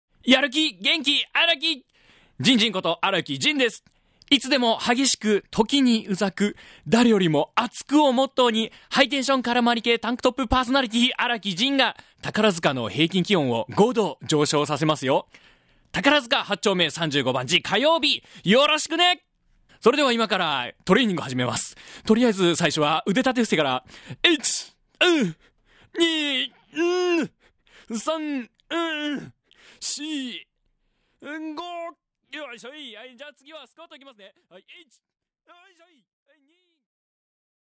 音声メッセージ